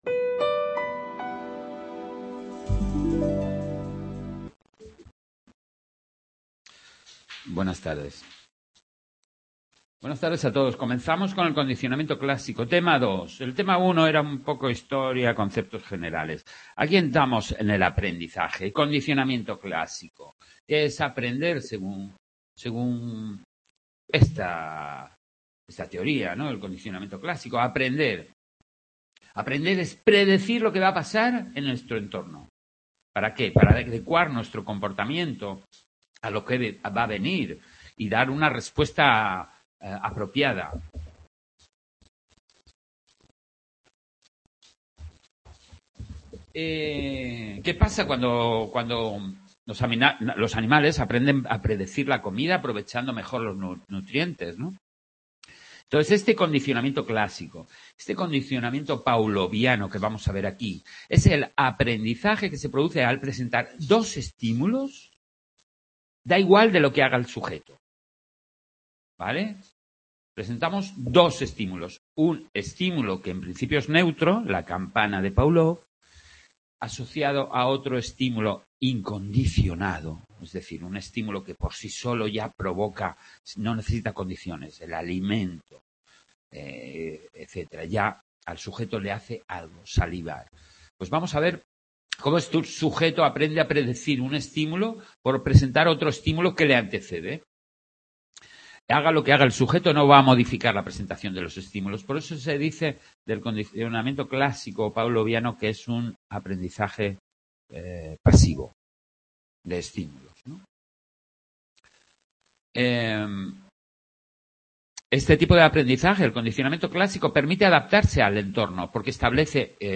en el aula de Sant Boi